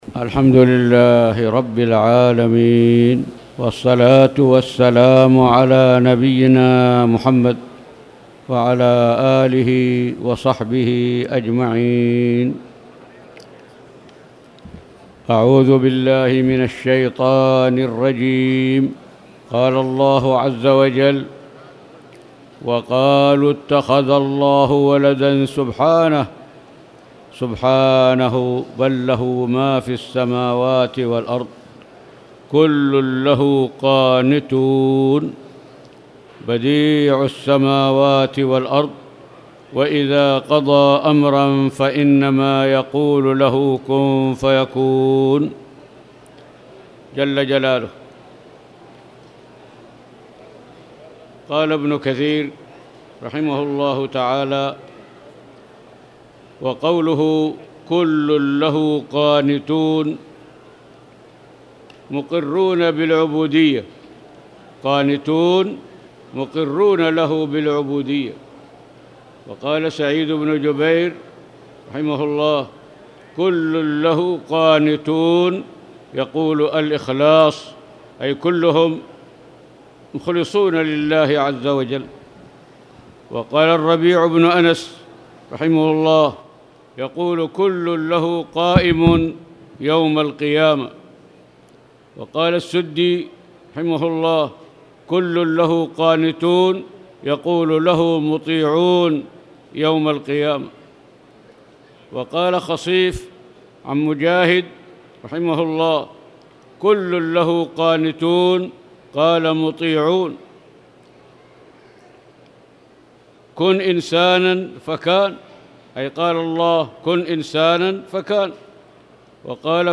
تاريخ النشر ١٩ صفر ١٤٣٨ هـ المكان: المسجد الحرام الشيخ